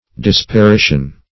Search Result for " disparition" : The Collaborative International Dictionary of English v.0.48: Disparition \Dis`pa*ri"tion\, n. [Cf. F. disparition.]